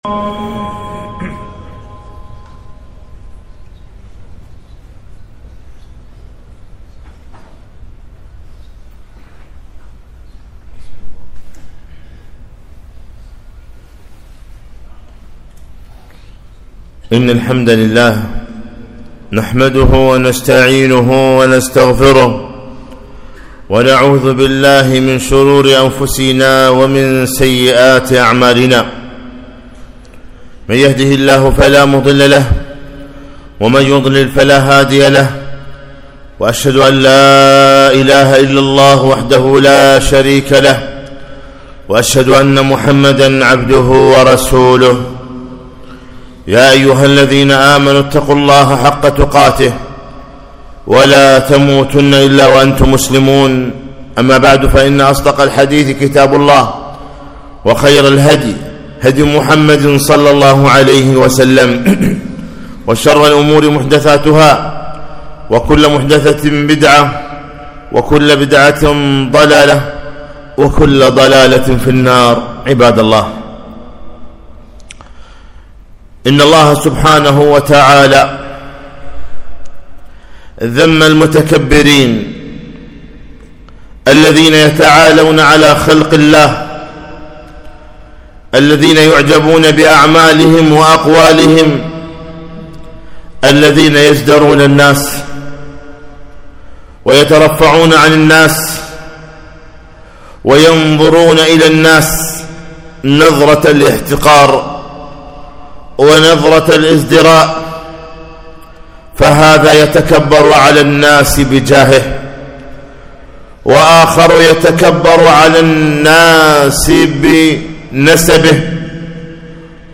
خطبة - ( ولا تمشِ في الأرض مرحا )